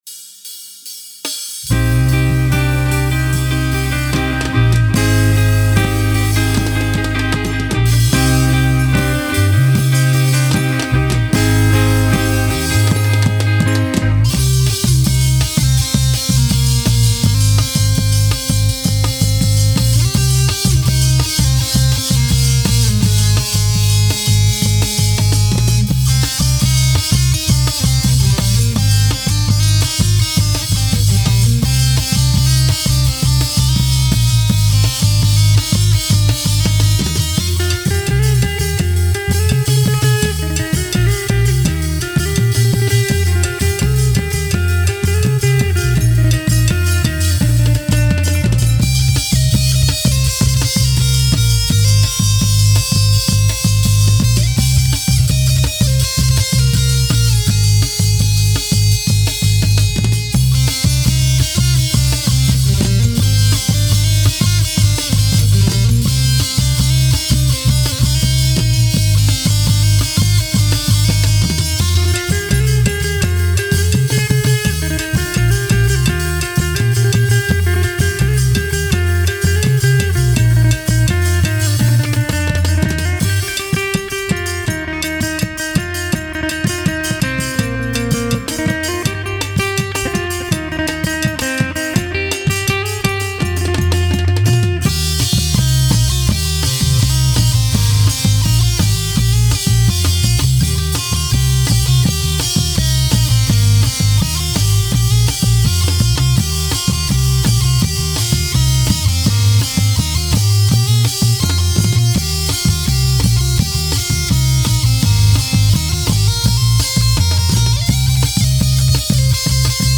Genre: World, Tai